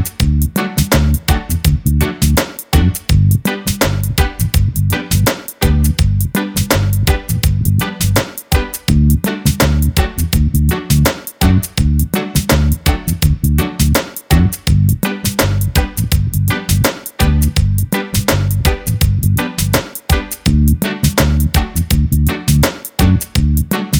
no Backing Vocals Reggae 3:21 Buy £1.50